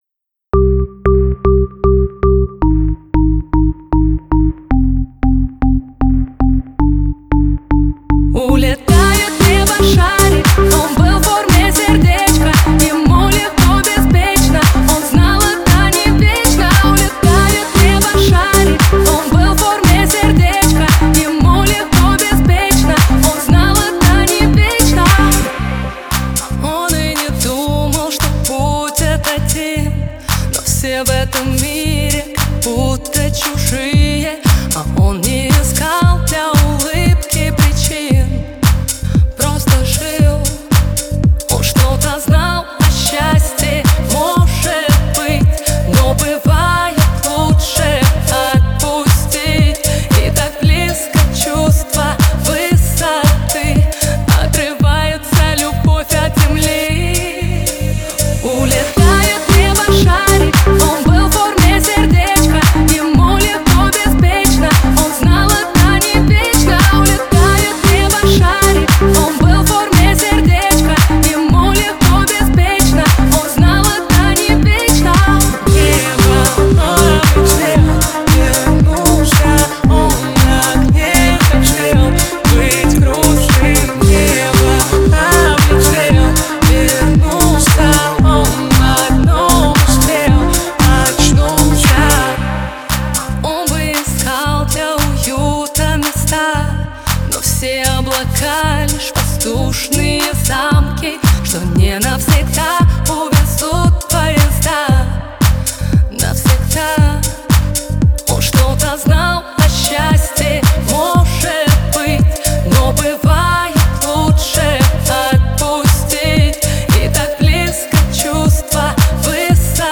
Русская поп музыка